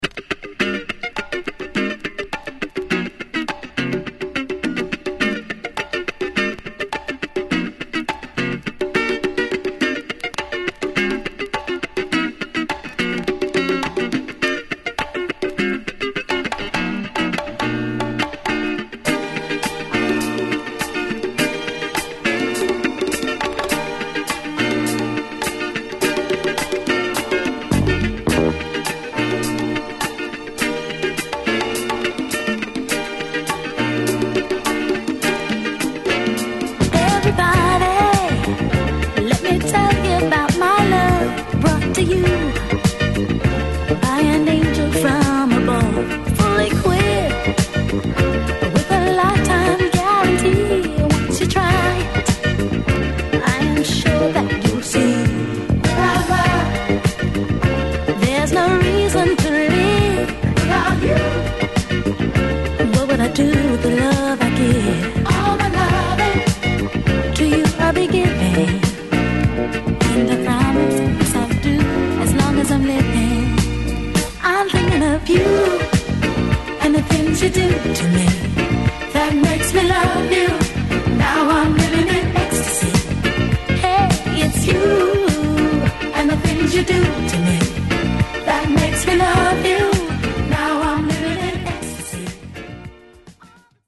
ホーム DISCO 80's 12' S
Format: 12 Inch